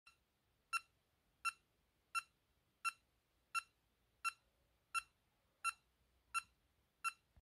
Здесь вы можете слушать и загружать сигналы, сопровождающие измерение уровня кислорода и пульса.
Звук и сатурация: как измерить